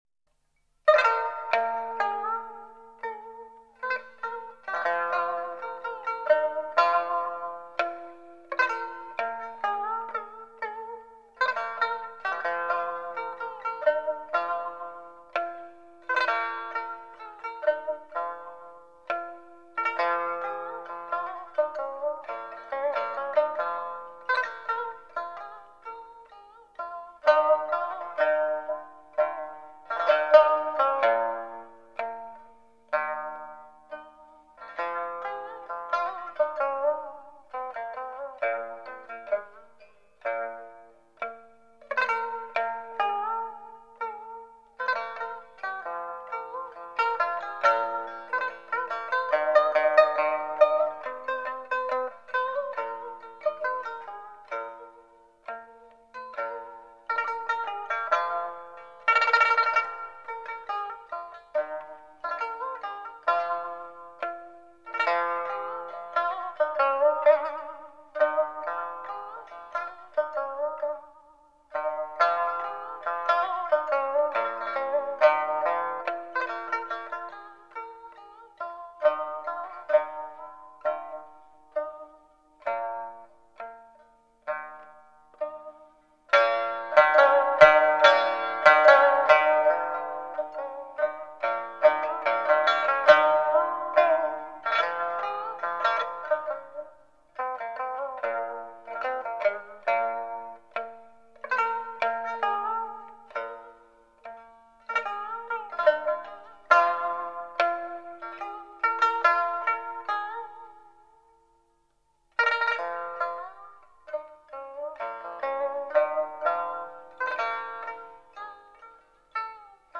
是这样安静的午后，窗明几净，雪落纷纷，听琵琶声声。。。
音乐类型: 民乐
它音域宽广，音色优美，技巧复杂，表现力较为丰富，为一般弹拨乐器所不可比拟。